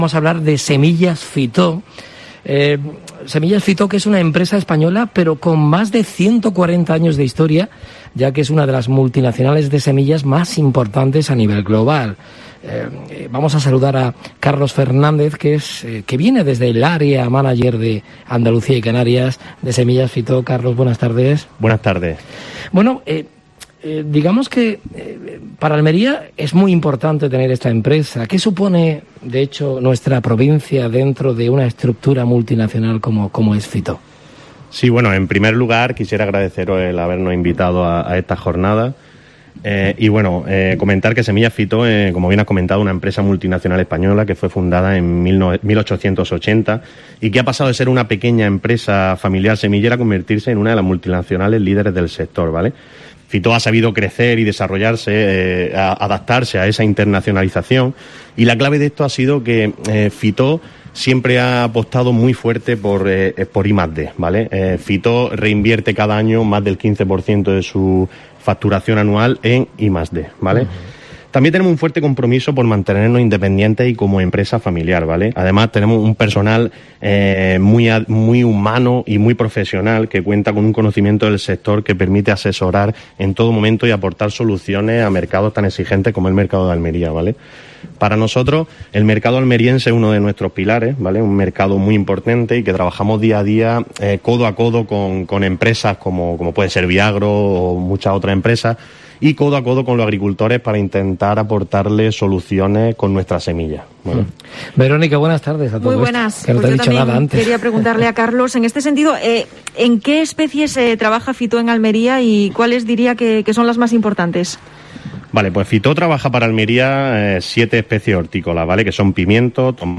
Cuarta y penúltima jornada de la semana dedicada por COPE Almería a 'Semillas, semilleros e injertos', que se están desarrollando en las instalaciones de Viagro.